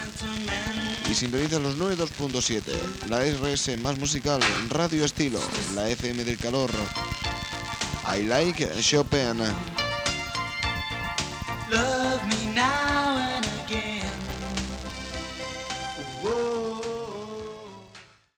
Identificació i tema musical.